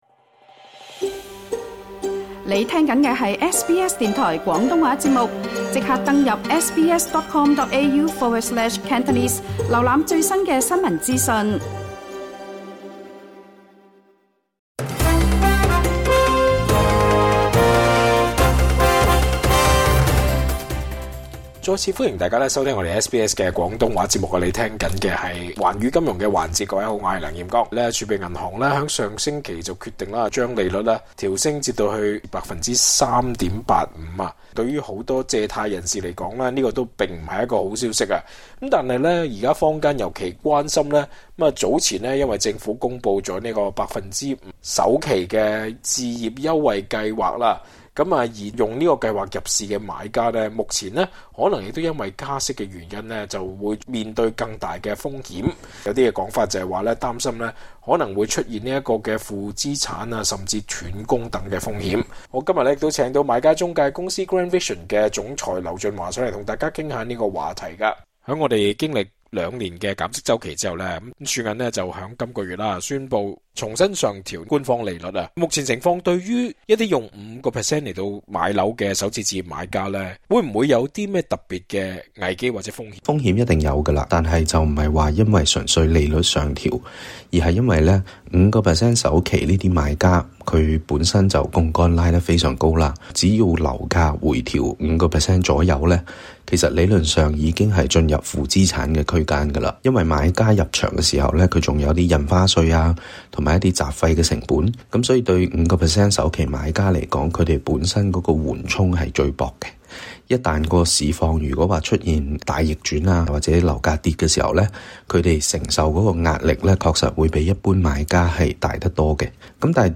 詳情請收聽足本訪問。